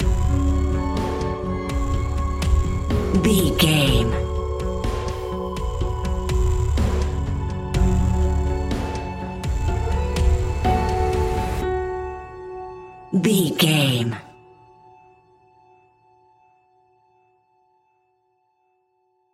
Ionian/Major
F♯
electronic
techno
trance
synths
synthwave
drone
glitch
instrumentals